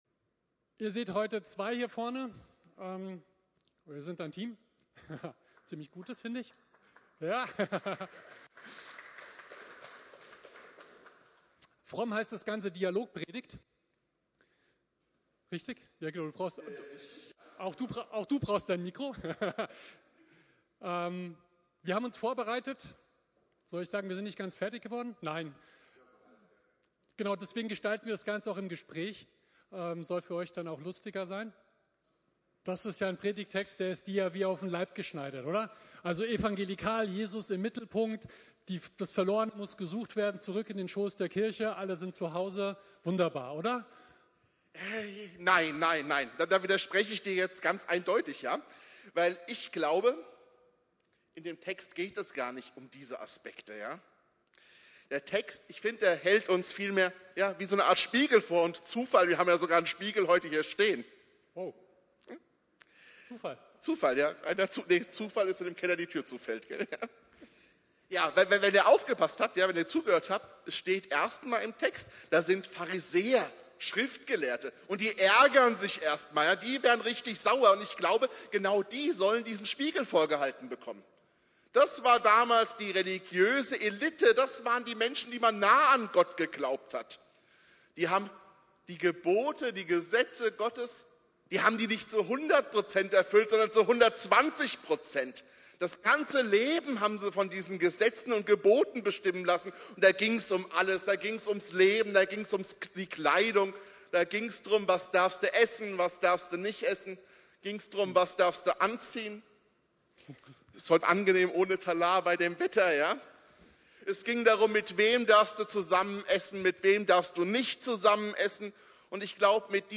Thema: Dialog Predigt: "Die Suchbewegung Gottes"